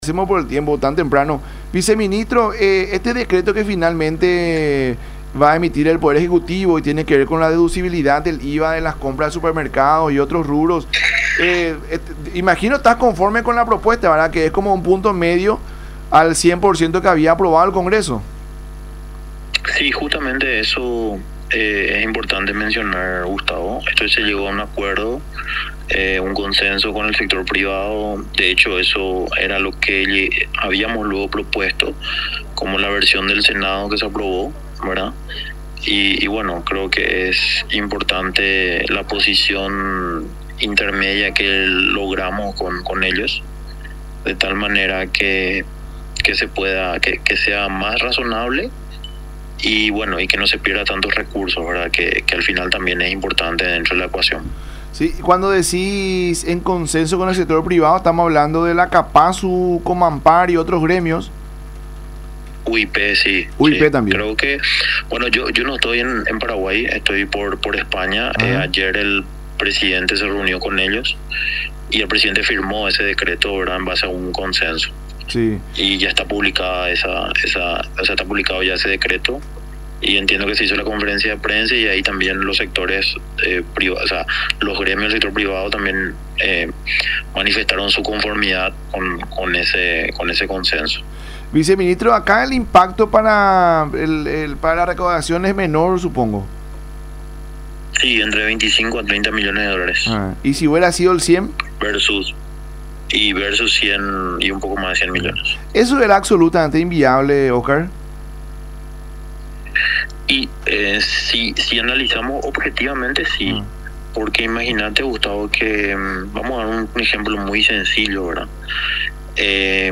“Se llegó a un consenso con el sector privado. Es importante la posición intermedia que logramos con ellos. Lo importante también es dentro de la ecuación que no se pierdan tantos recursos”, expuso Orué en conversación con La Mañana De Unión por Unión TV y radio La Unión.
02-OSCAR-ORUE.mp3